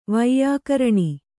♪ vaiyākaraṇi